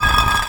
brick_m2.wav